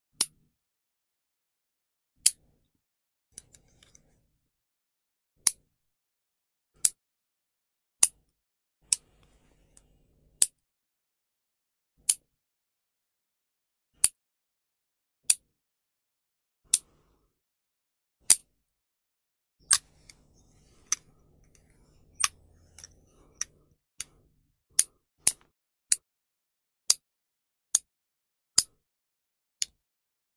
Tiếng Cắt móng tay, Bấm móng tay…
Thể loại: Tiếng động
Description: Tiếng cắt móng tay, bấm móng tay, tách móng, tiếng cạch, lách tách vang lên giòn tan, sắc nét và đều đặn. Âm thanh này tạo cảm giác vừa thư giãn vừa chân thực, thường được dùng trong các video ASMR, làm đẹp hoặc vlog chăm sóc cá nhân.
tieng-cat-mong-tay-bam-mong-tay-www_tiengdong_com.mp3